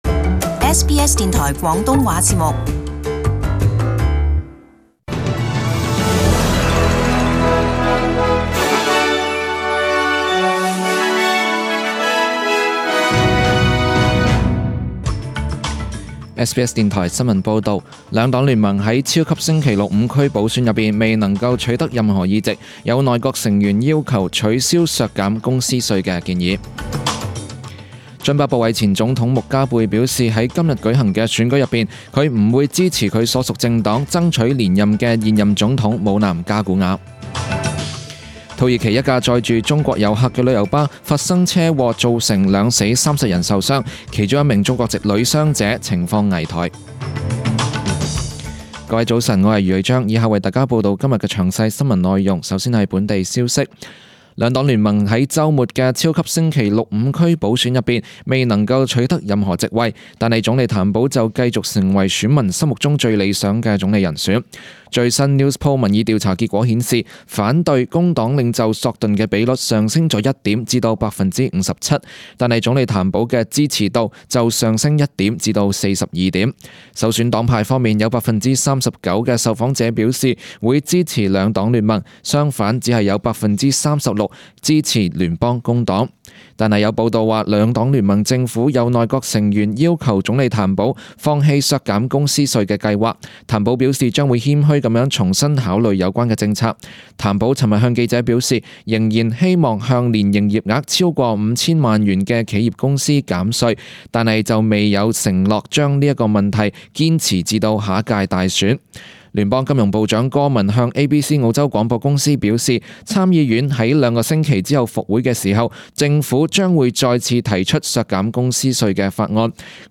SBS中文新闻 （七月三十日）
请收听本台为大家准备的详尽早晨新闻。